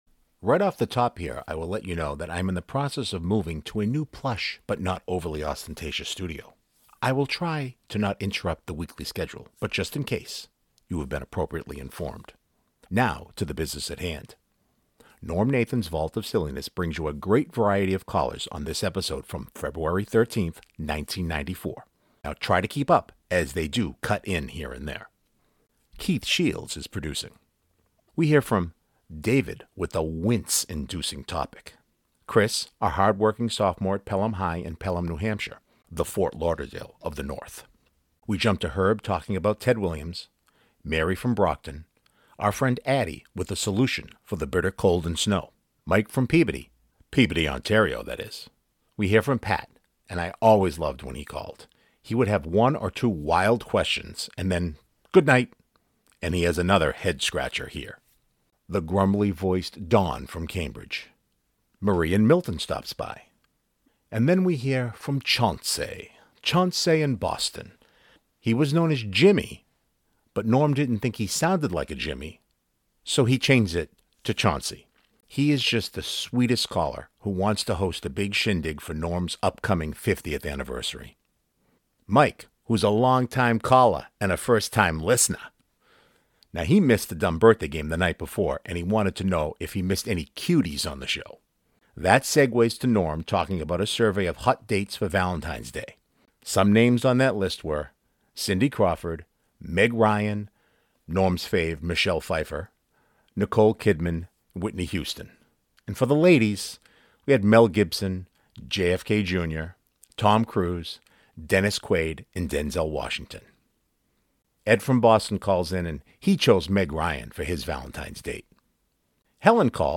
Now try to keep up as they do cut in here and there.